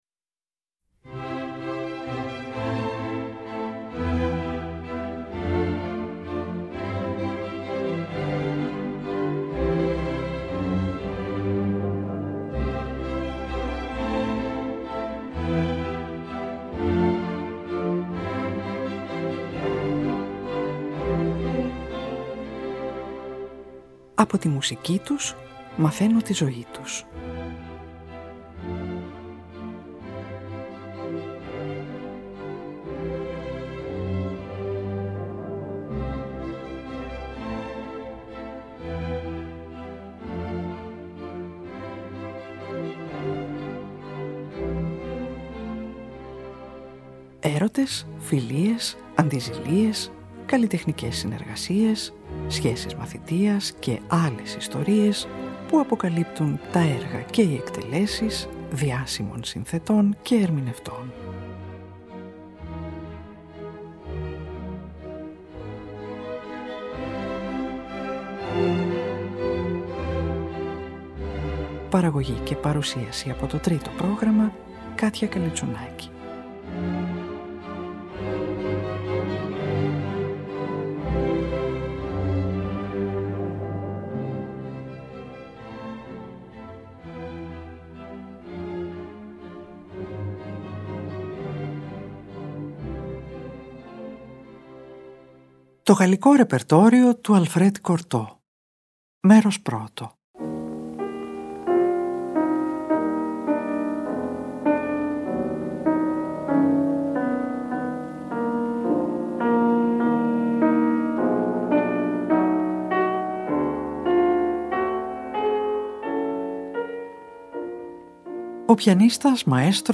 Ο επιφανής Γαλλοελβετός πιανίστας